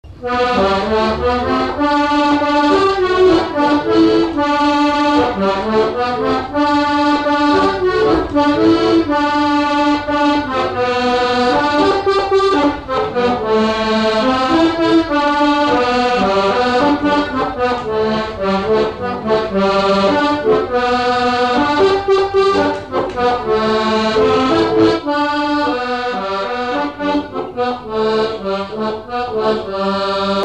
Genre strophique
Témoignages et chansons
Catégorie Pièce musicale inédite